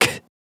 R - Foley 210.wav